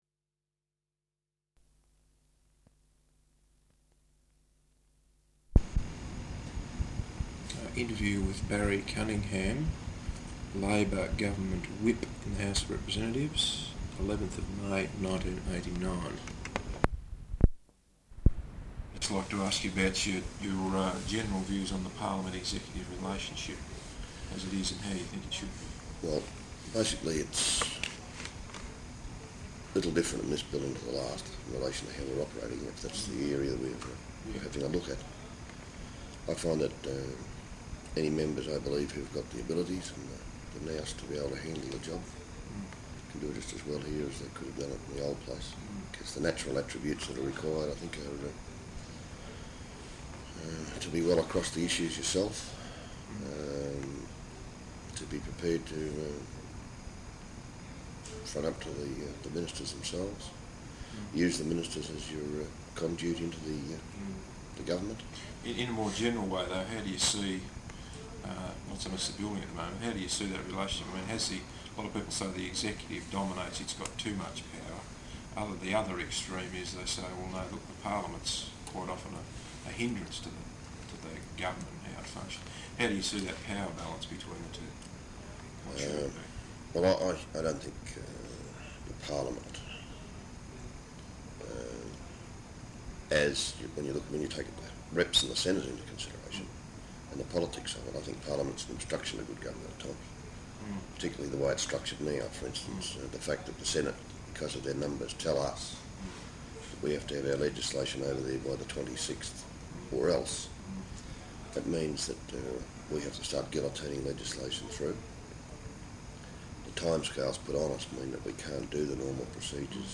Interview with Barry Cunningham, Labor government Whip in the House of Representatives, Parliament House, Canberra, 11th May 1989.